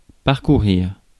Ääntäminen
IPA: [paʁ.ku.ʁiʁ]